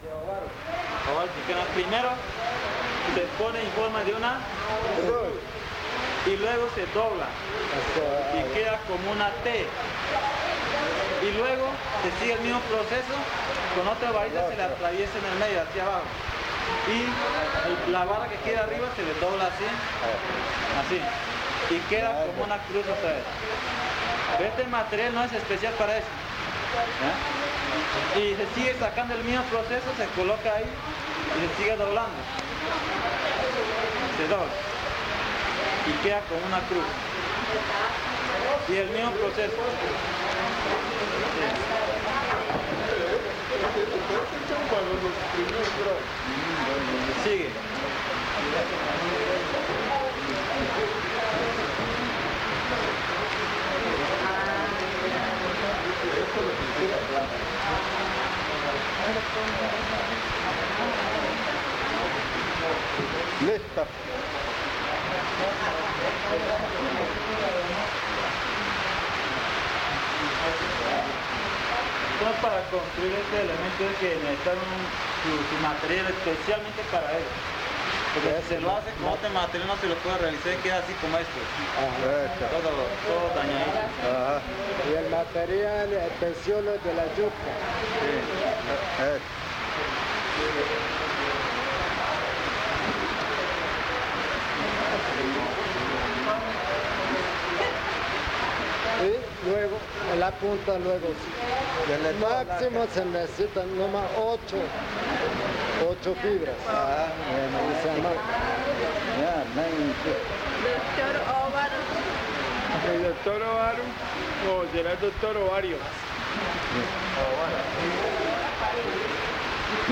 Tipisca, Amazonas (Colombia)